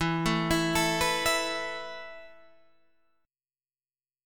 Esus4 chord